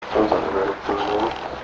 Voice EVP
Whilst walking around I did several recordings with my Sony B300 recorder, obviously no background file was usued. All evp’s captured were not heard at the time it was only later when I reviewed my recordings that I found the ones that I am posting here plus several more that were a little too hissy to include.
I-was-afraid-those-woods.mp3